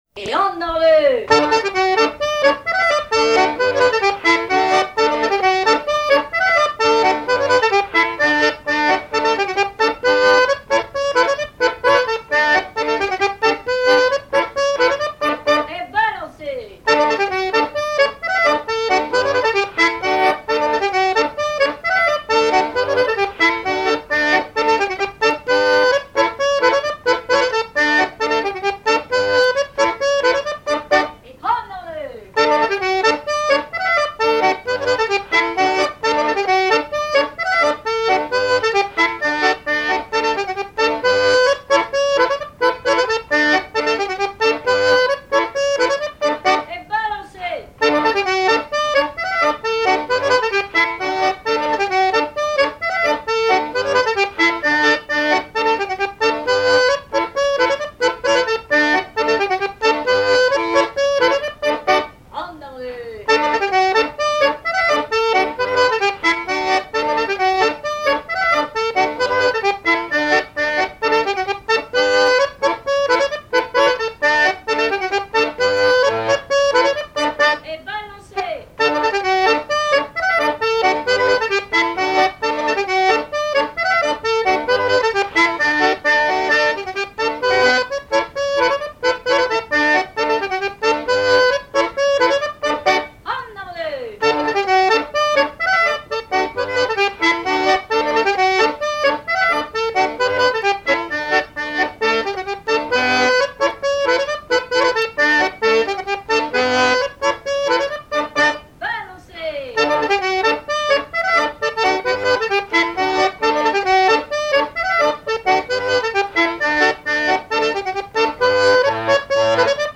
danse : branle : avant-deux
Répertoire d'airs à danser
Pièce musicale inédite